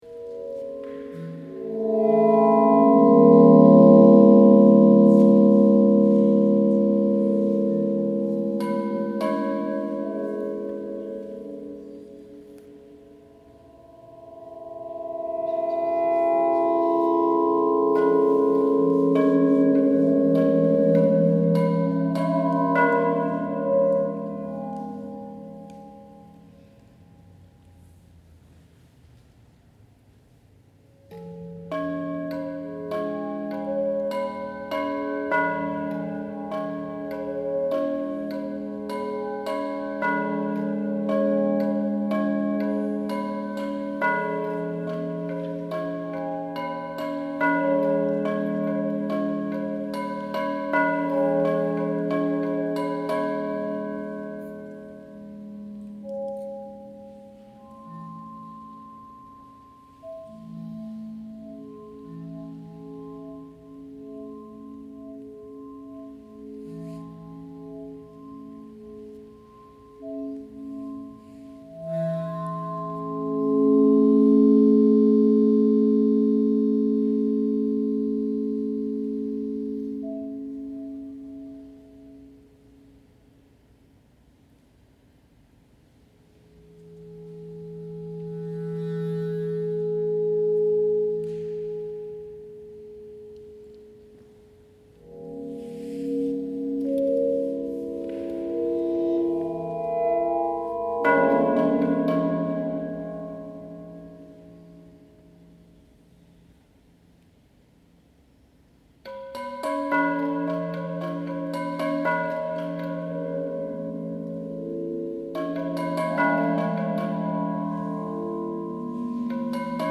Using a transducer placed on each container, acoustic waves will vibrate the ceramic bowls like a loudspeaker membrane. Each bowl or container, by its size and thickness, will produce a unique sound within a chorus whose harmony will depend on the potter’s hand.
Excerpt from the concert on 20.9.24 at the Ariana Museum in Geneva.